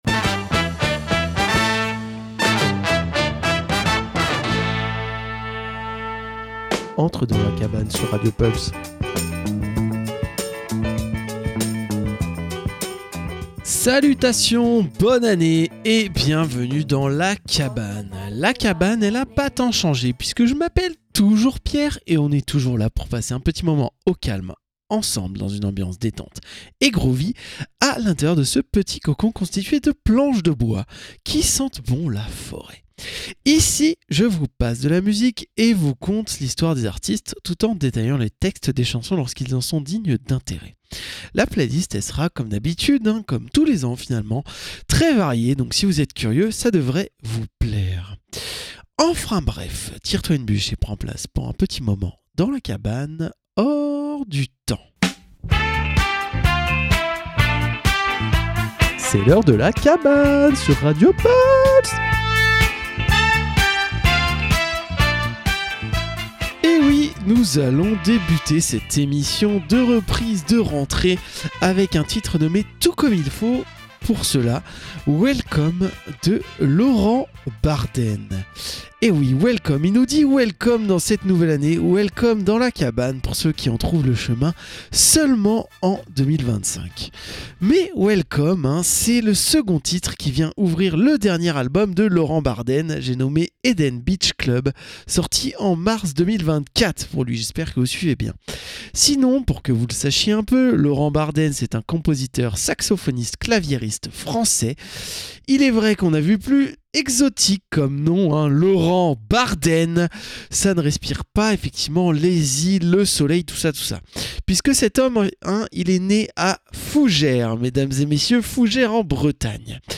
La cabane, détente dans une ambiance chill/groovy avec une playlist éclectique allant du rock à la funk en passant par la pop, le rap ou l'électro. Au programme : écoute et découverte ou redécouverte d'artistes et explication des paroles de grands classiques de la musique !